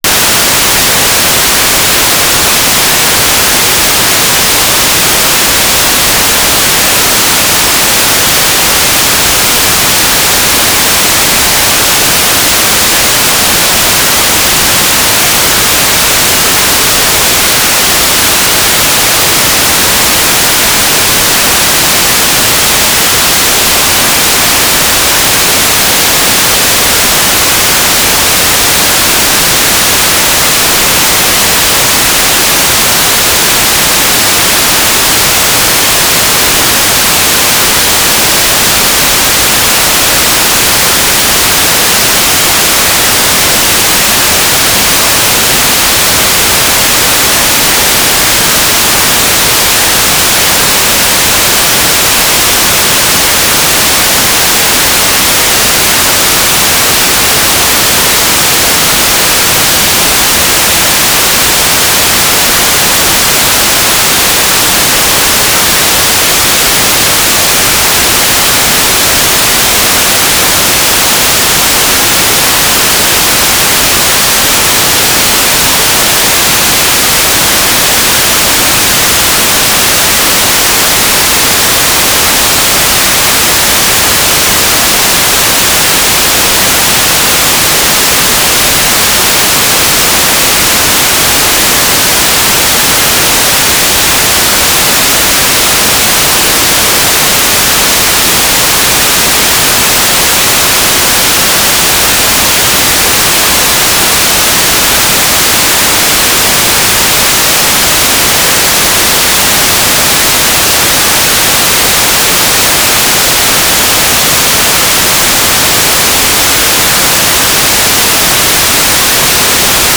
"transmitter_description": "Mode U - GMSK2k4 - USP",
"transmitter_mode": "GMSK USP",